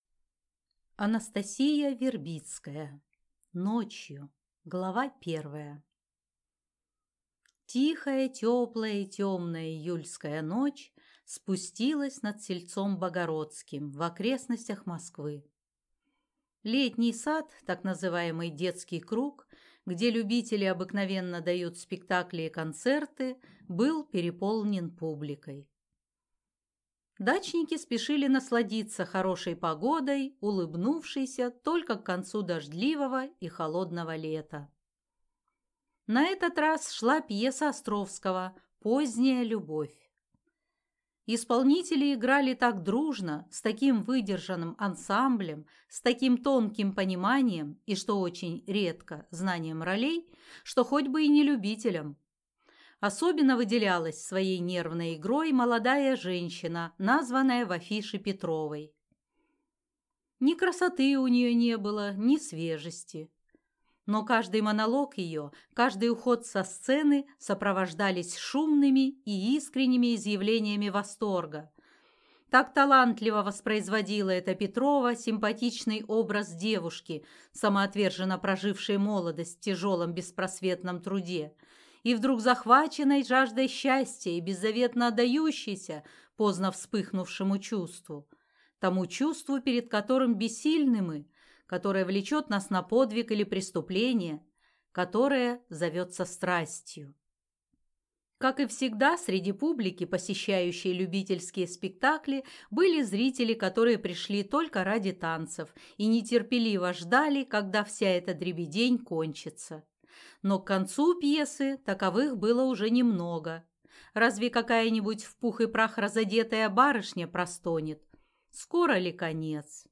Аудиокнига Ночью | Библиотека аудиокниг
Прослушать и бесплатно скачать фрагмент аудиокниги